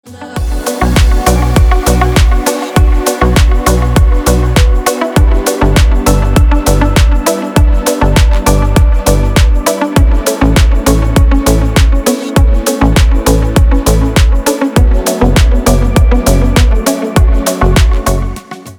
Deep House рингтон для мобильного